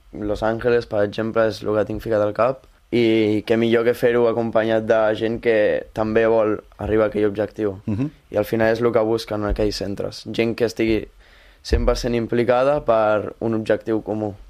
Ho ha explicat en una entrevista al programa La Banqueta de Ràdio Calella Televisió.